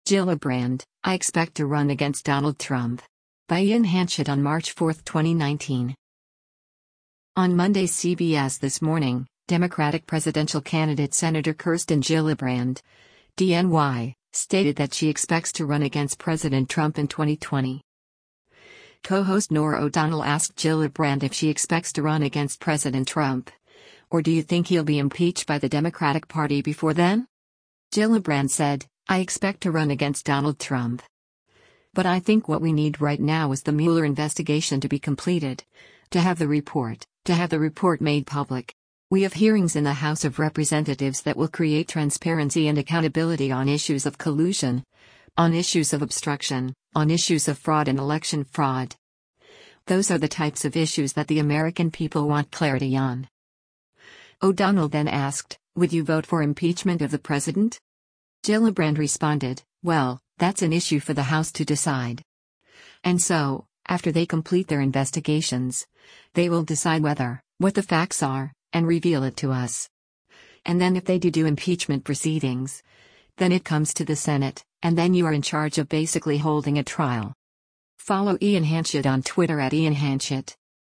On Monday’s “CBS This Morning,” Democratic presidential candidate Senator Kirsten Gillibrand (D-NY) stated that she expects to run against President Trump in 2020.
Co-host Norah O’Donnell asked Gillibrand if she expects to run against President Trump, “or do you think he’ll be impeached by the Democratic Party before then?”